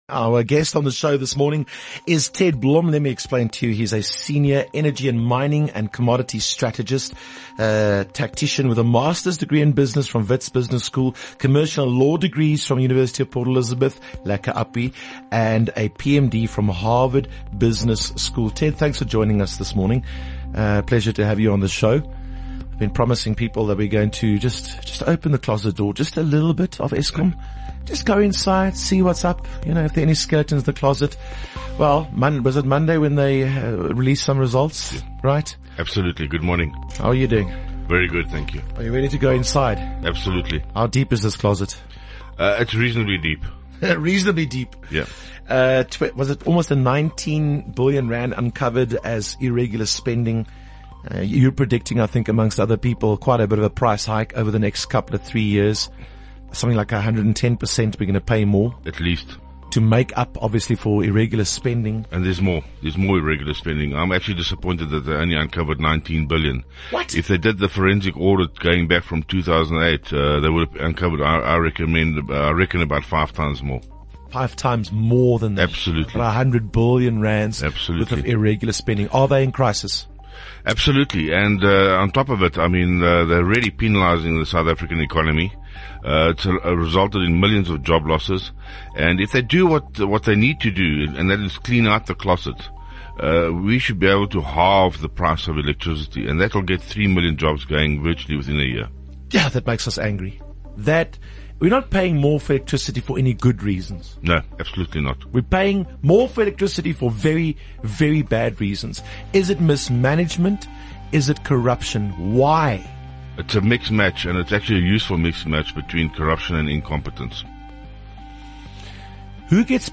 Speaking about the corruption at Eskom on Jacaranda FM.